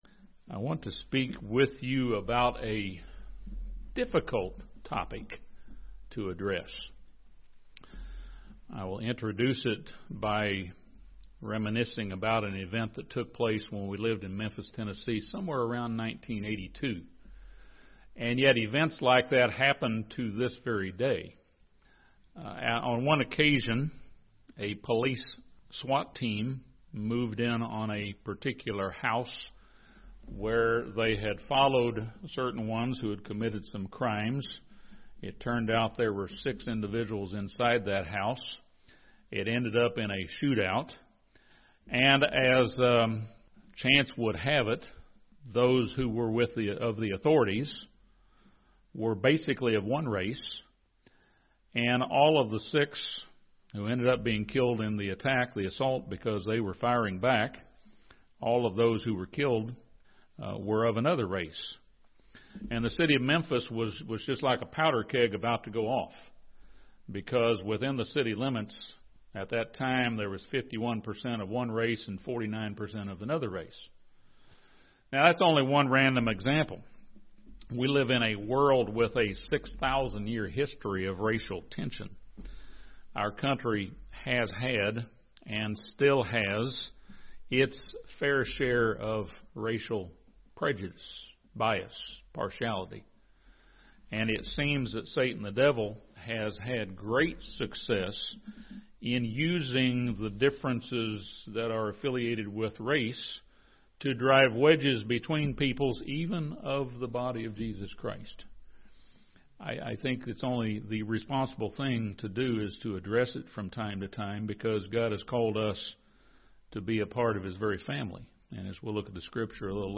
This sermon addresses the evil specter of racism in the world around us.